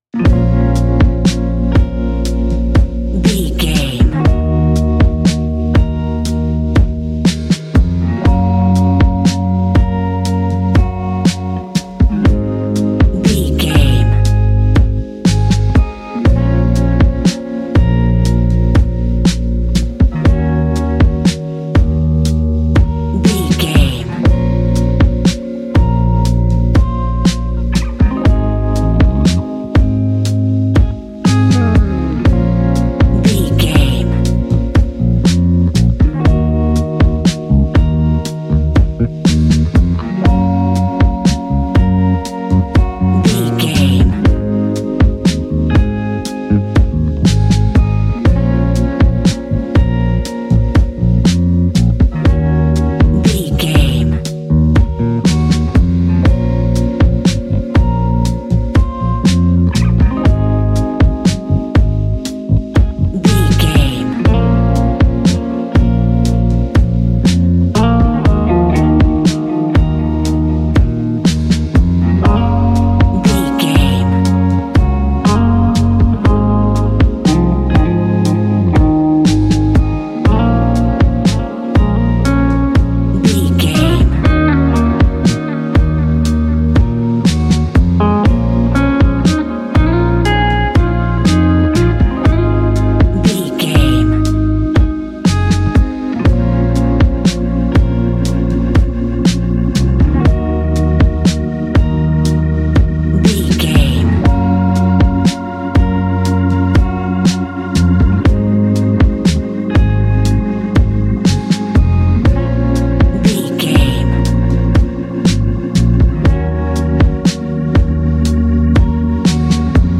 Ionian/Major
A♯
laid back
Lounge
sparse
new age
chilled electronica
ambient
atmospheric
instrumentals